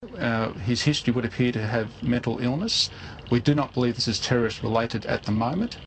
Plane